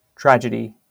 IPA/ˈtrædʒədi/ wymowa amerykańska?/i